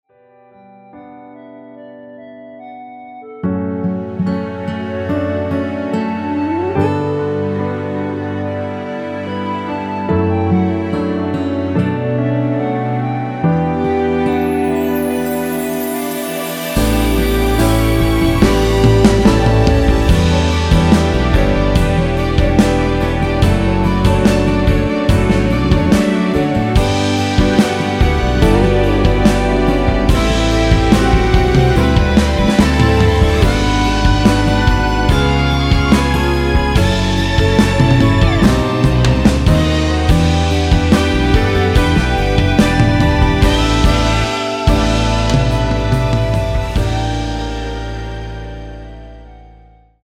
노래가 바로 시작 하는 곡이라 전주 만들어 놓았으며
원키에서(+2)올린 멜로디 포함된 1절후 후렴으로 진행되게 편곡한 MR 입니다.(미리듣기및 가사 참조)
◈ 곡명 옆 (-1)은 반음 내림, (+1)은 반음 올림 입니다.
앞부분30초, 뒷부분30초씩 편집해서 올려 드리고 있습니다.